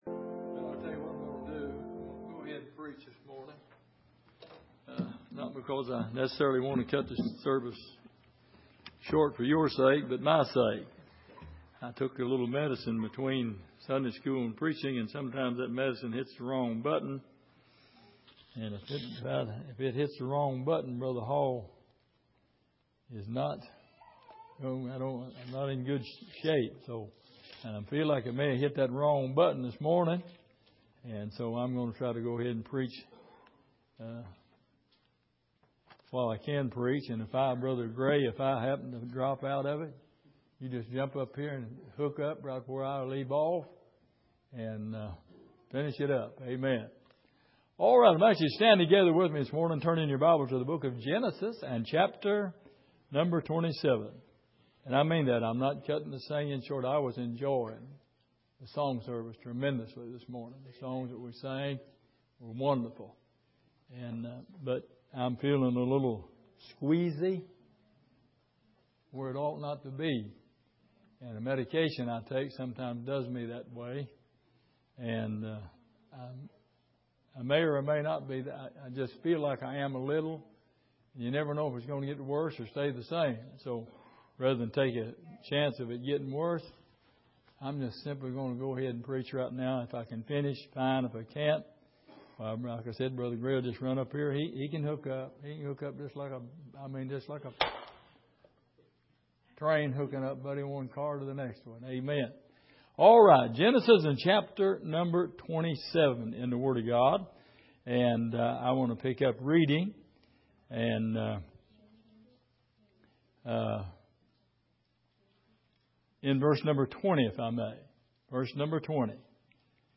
Miscellaneous Passage: Genesis 27:20-29 Service: Sunday Morning Jacob’s Blessings « The Christian’s Greatest Weapon